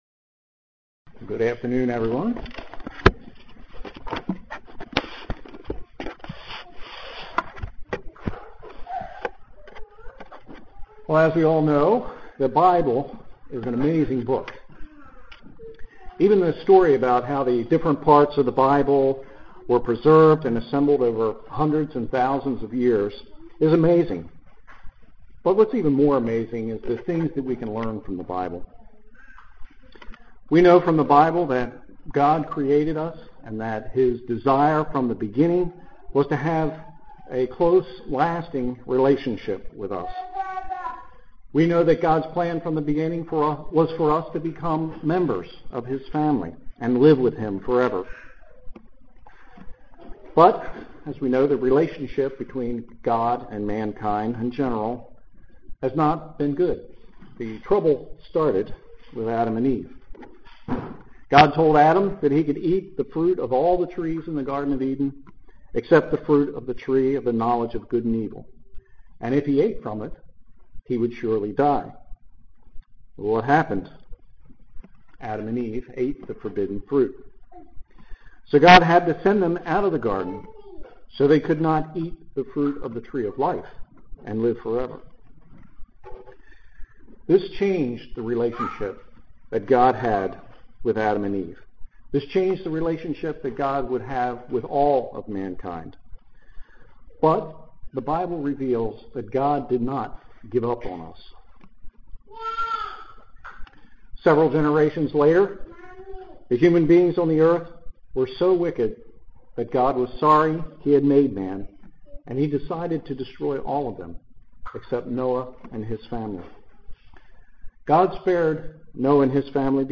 Sermon
Given in Columbia, MD